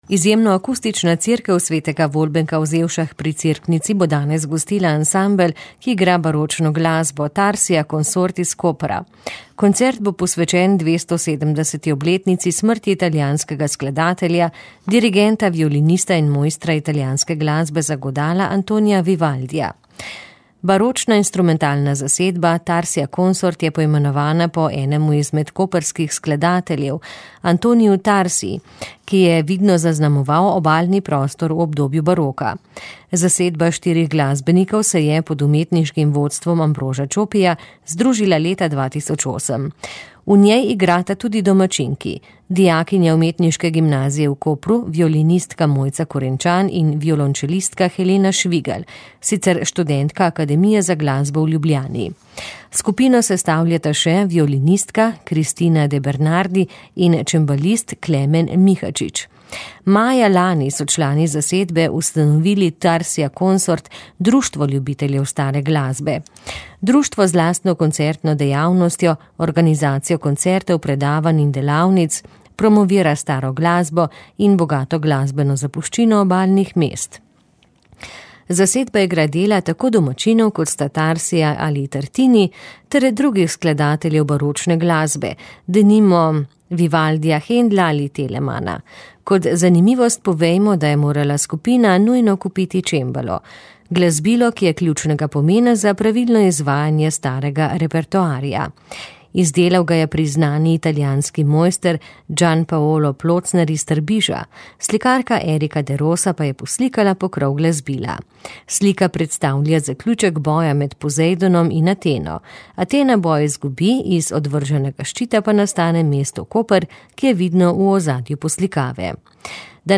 P242-69_zelse-koncert_vivaldi.mp3